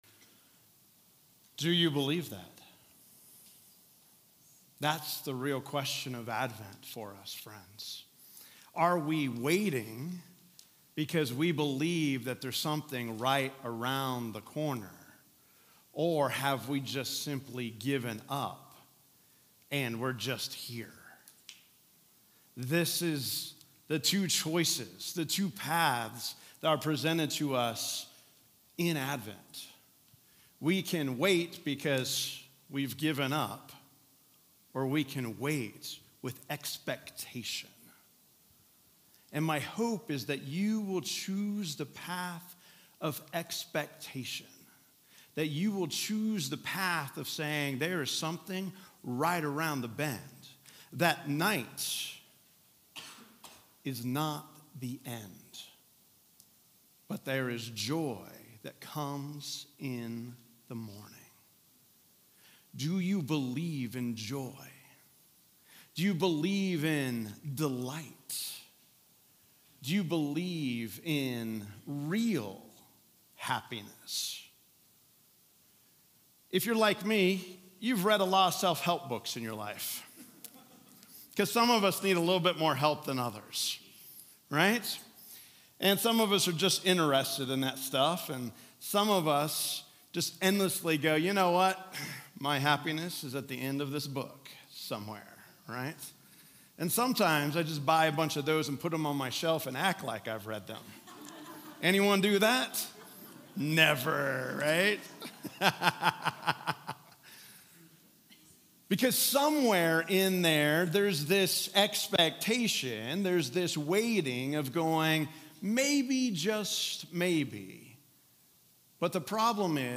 Sermons | Grace Presbyterian Church